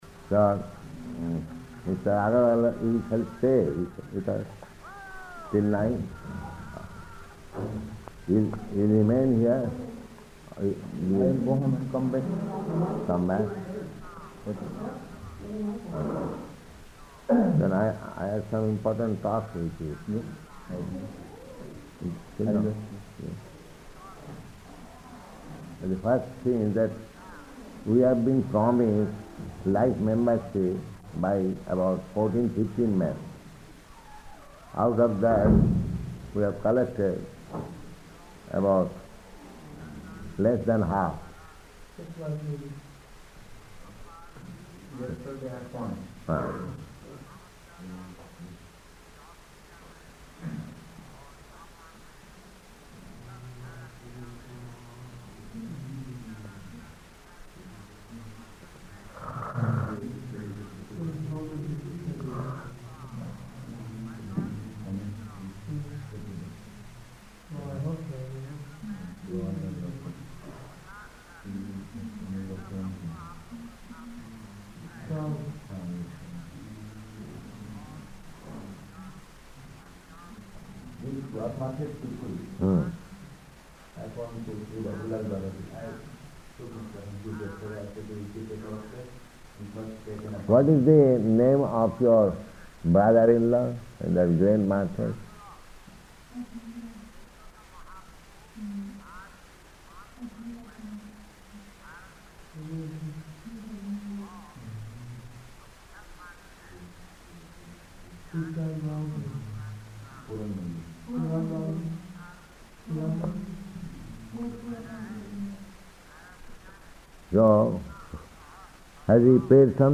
Room Conversation
-- Type: Conversation Dated: December 15th 1970 Location: Indore Audio file